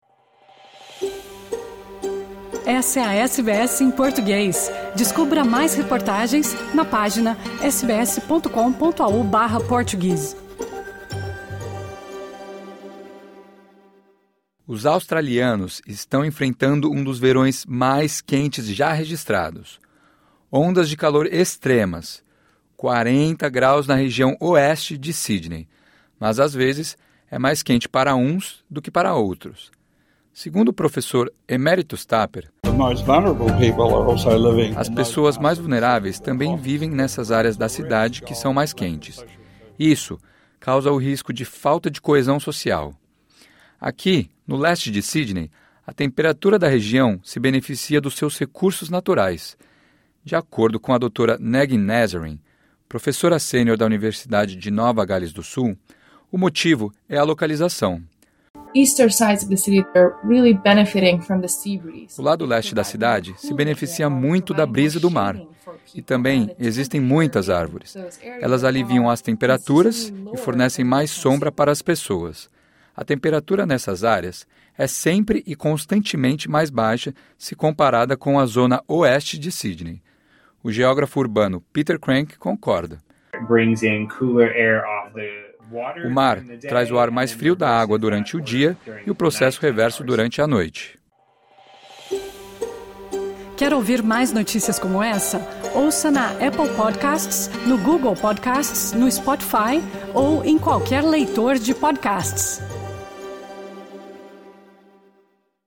Em Penrith, no oeste de Sydney, moradores disseram à SBS Examines que estavam sentindo o calor.